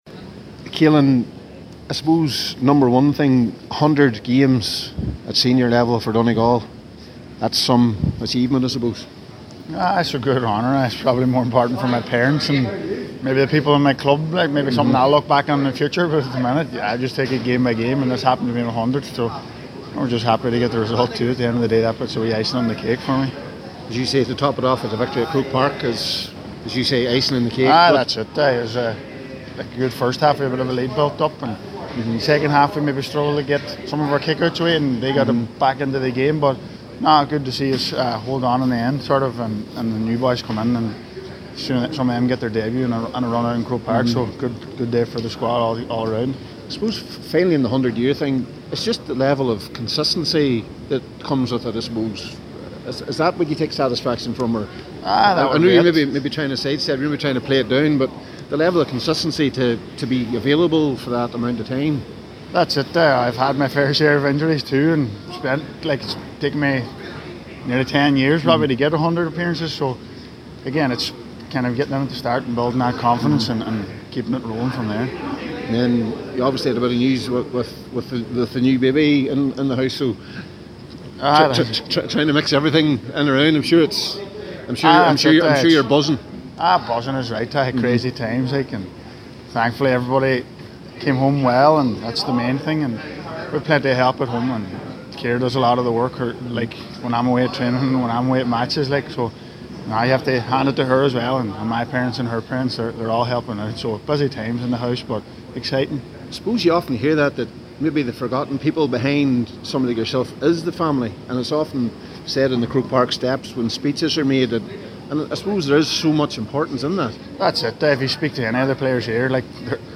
at full time at GAA HQ…